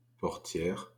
A portière (French pronunciation: [pɔʁtjɛʁ]